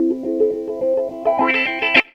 GTR 24 AM.wav